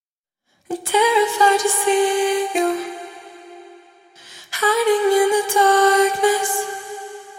我们将创建一个额外的发送和返回通道，然后在其中放置一个 RAUM 混响插件。
人声现在听起来被淹没在混响中了，以致于我们失去了一些人声原有的临场感和力量感。